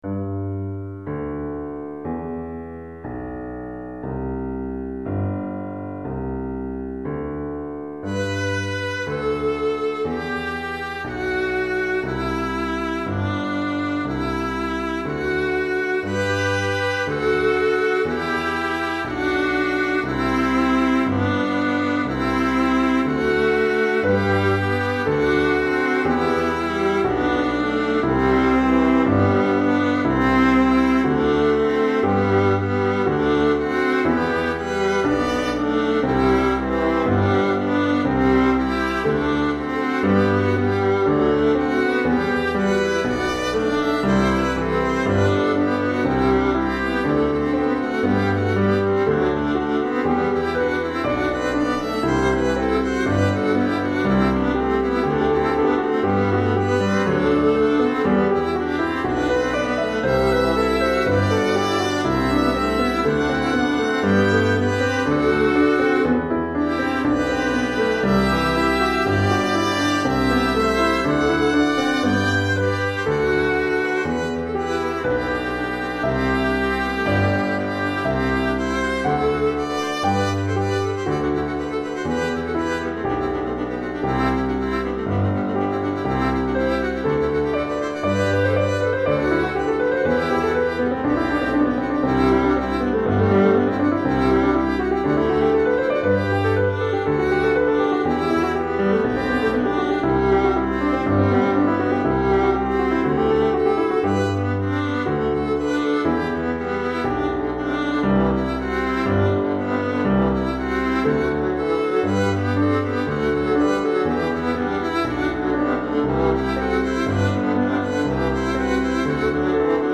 Répertoire pour Alto - 2 Altos et Piano